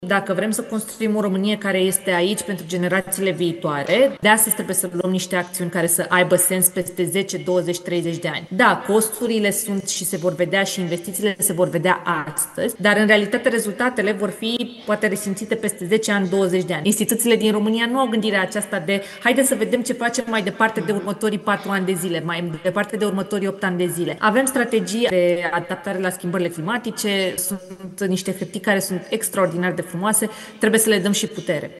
Diana Buzoianu a vorbit – la summitul Climate Change, care se desfășoară la București – și despre urgența cu care politicienii trebuie să acționeze pentru a asigura generațiilor viitoare o țară sigură.
Ministra Mediului, Diana Buzoianu: „Instituțiile din România nu au această gândire de tipul «hai să vedem ce facem mai departe în următorii patru ani»”
A patra ediție se desfășoară între 20 și 25 octombrie, la Palatul Parlamentului și în alte locații din România și Europa Centrală și de Est.